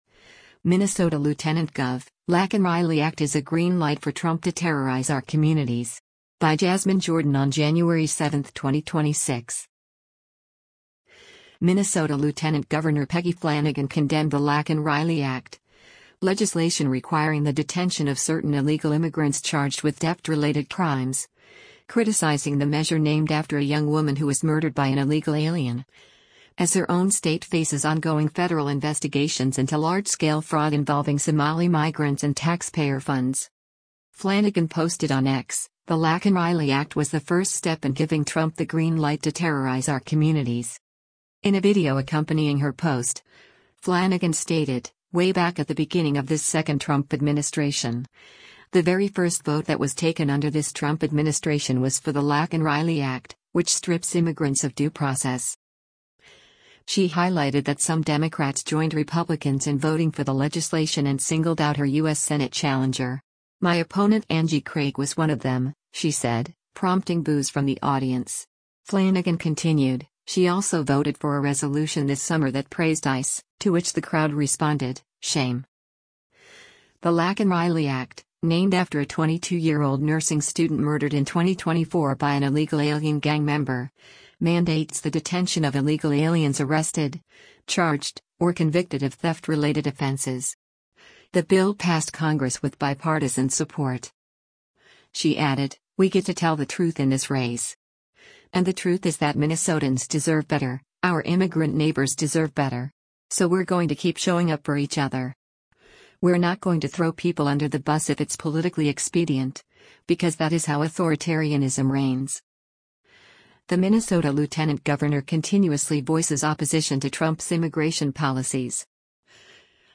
“My opponent Angie Craig was one of them,” she said, prompting boos from the audience. Flanagan continued, “She also voted for a resolution this summer that praised ICE,” to which the crowd responded, “Shame.”